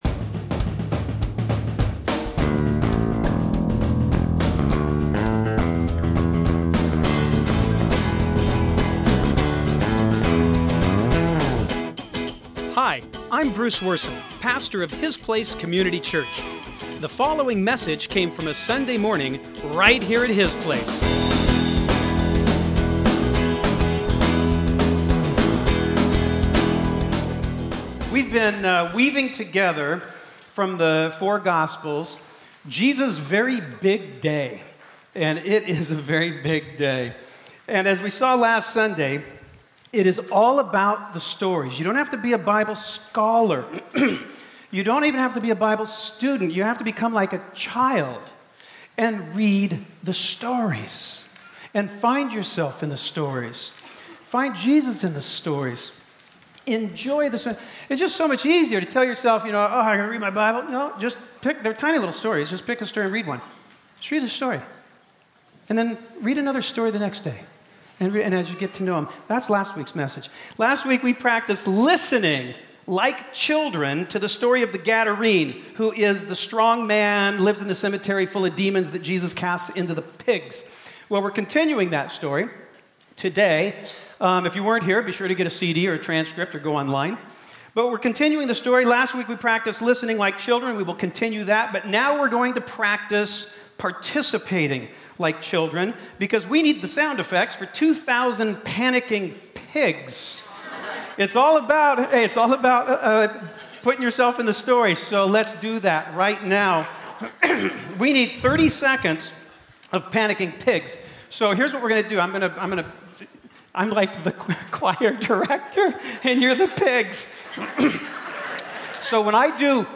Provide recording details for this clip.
Sunday morning messages from His Place Community Church in Burlington, Washington. These surprisingly candid teachings incorporate a balanced mix of lighthearted self-awareness and thoughtful God-awareness.